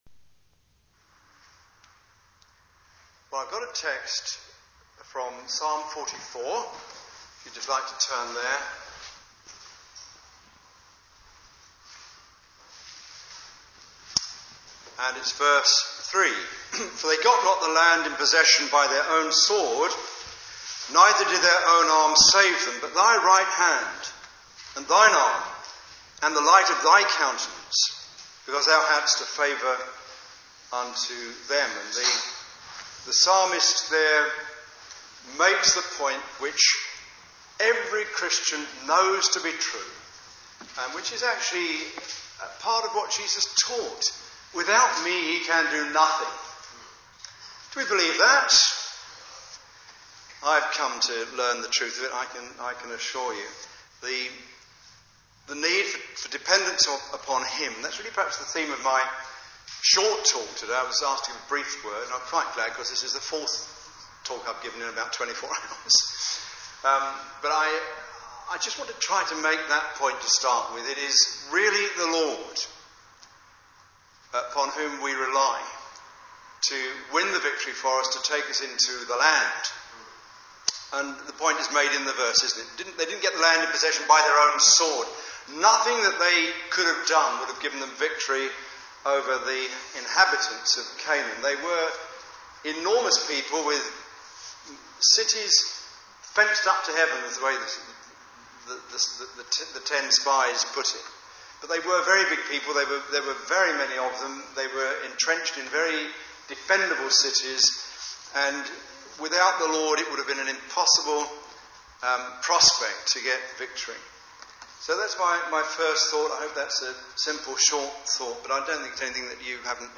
Psalm 44:3 Service Type: Sunday Evening Service « Psalm 73:25-28